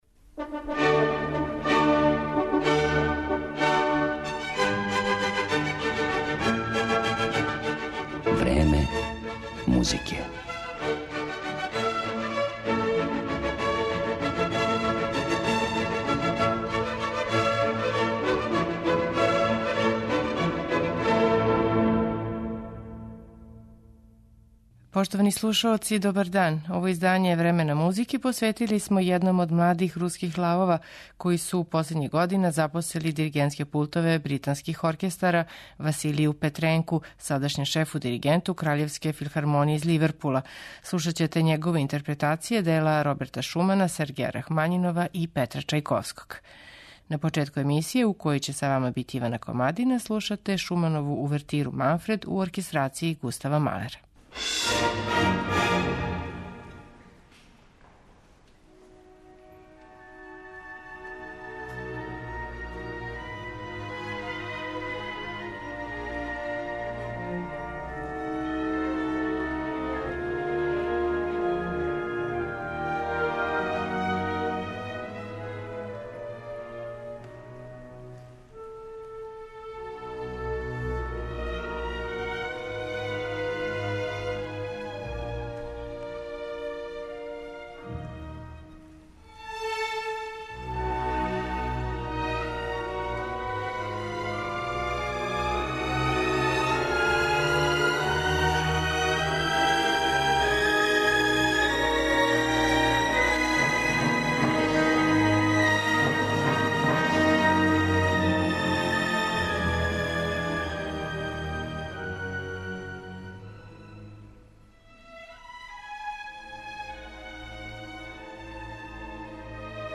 Музички портрет диригента Василија Петренка